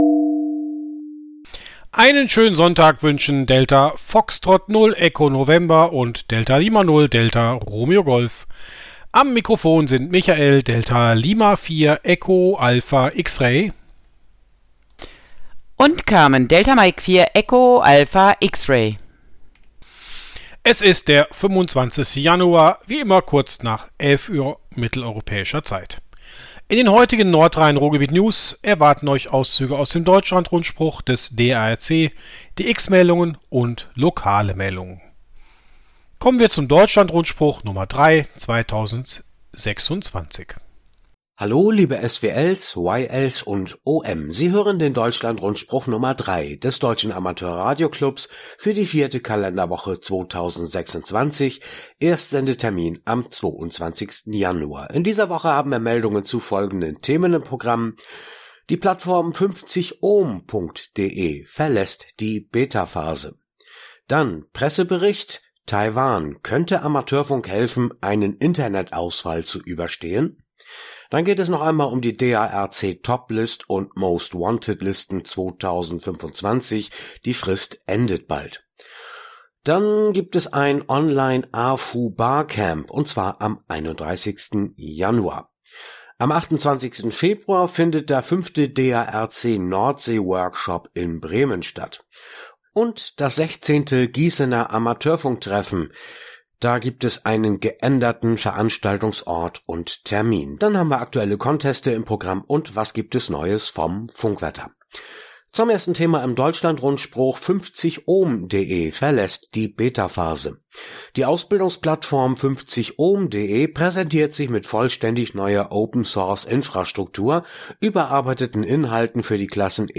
Aktueller Rundspruch